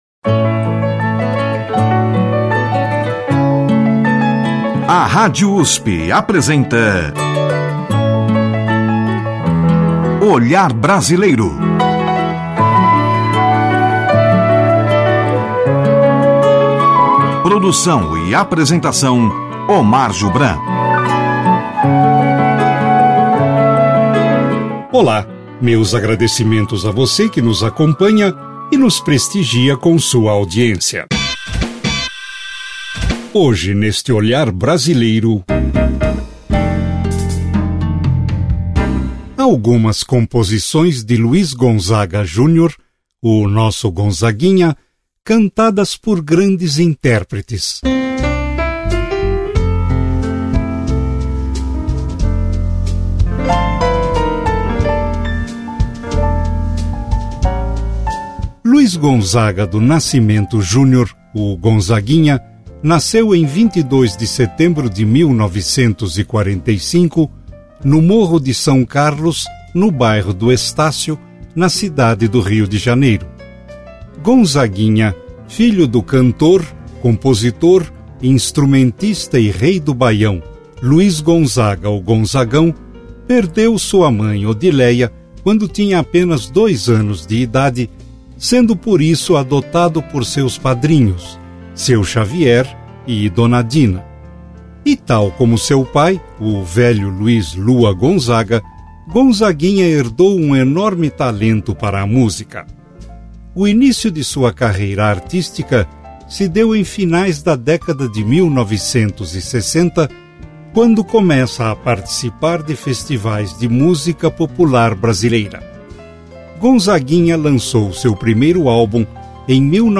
Entre os cantores estão Nana Caymmi, Maria Bethânia, Gal Costa e Daniel Gonzaga, filho do cantor e neto de Gonzagão